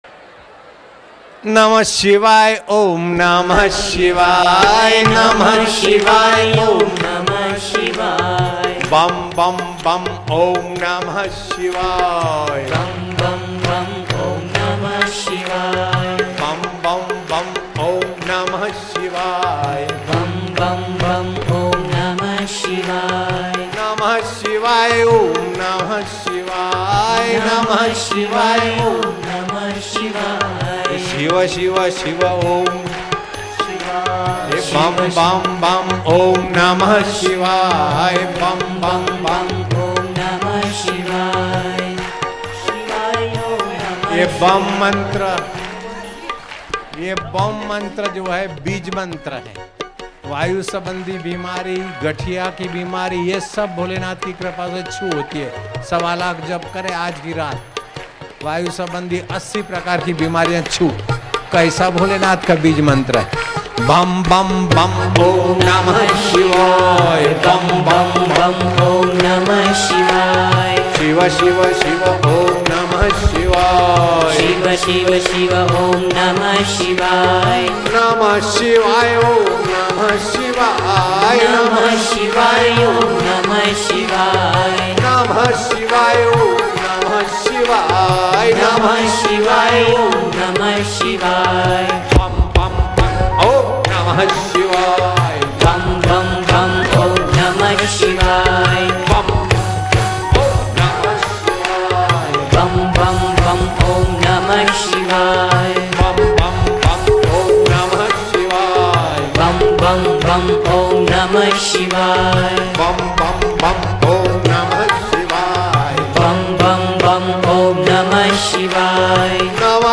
Bam-Bam-Om-Namah-Shivaya-Kirtan.mp3